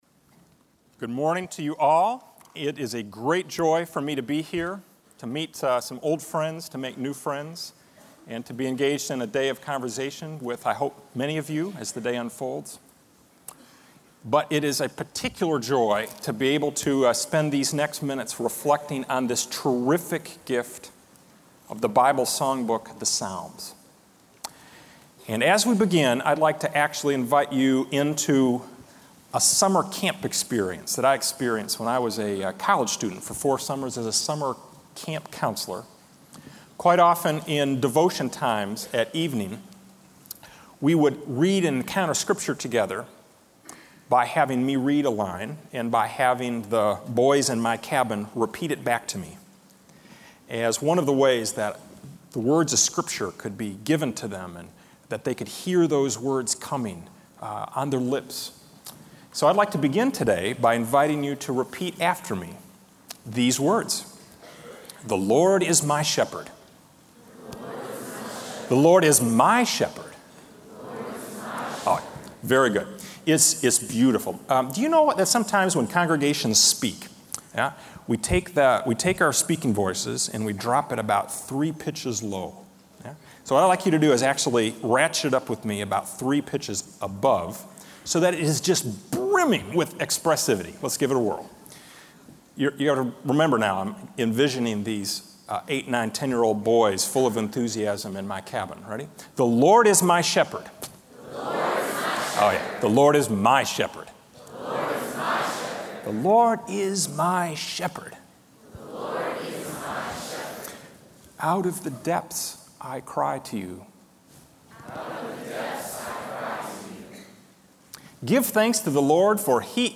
Chapel
Address: The Psalms: A Gymnasium for the Soul